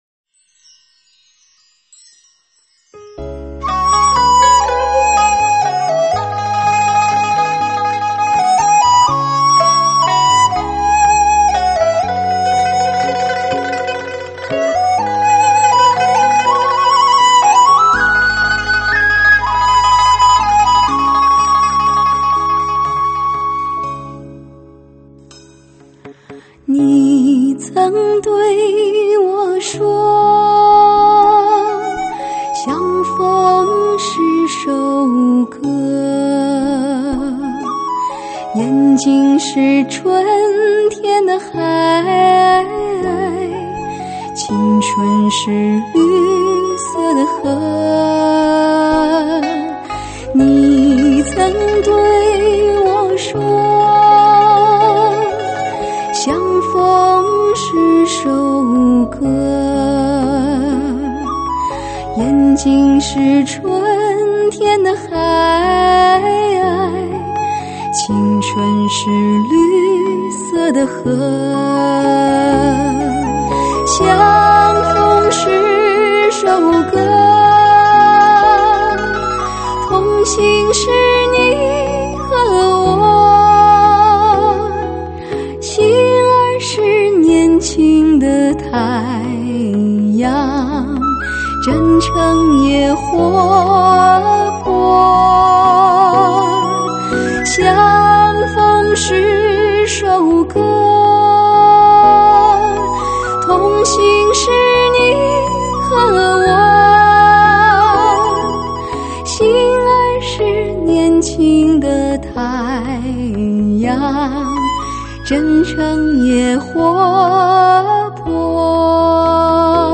每一首，都有难言的意趣....美妙的人声和器乐独奏，您自己品味吧...